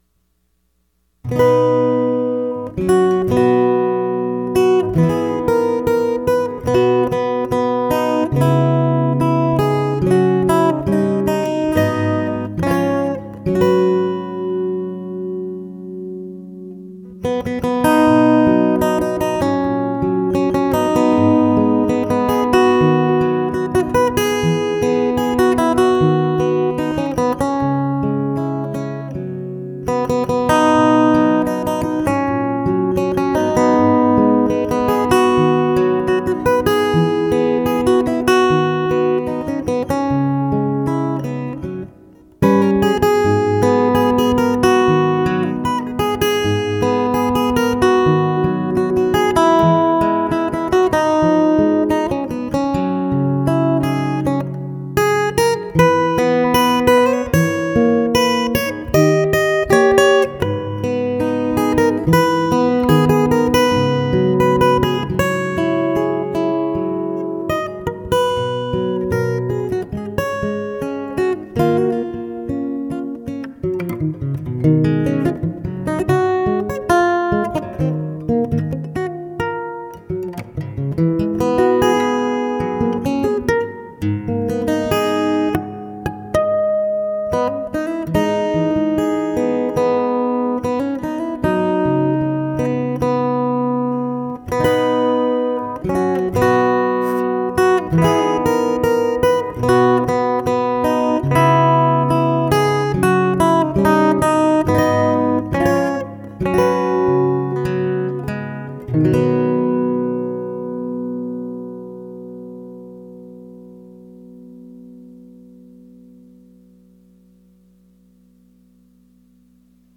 もう一台、ナイロン弦仕様の初代肉球ギターがありますが、こちらはピックアップが付いて無く、マイクを棚に片づけているため、不参加です。 ナイロン弦肉球ギターも久しく弾いていませんが、ミニ肉球ギターも久々に弾きました。
ミニ肉球ギターの方が、やはりメロディーが透き通ているというか、伴奏が邪魔しない程度の音量になるので、ジブリ音楽向きですね。 ドレッド肉球ギターは、箱鳴りの倍音が気持ちよいです。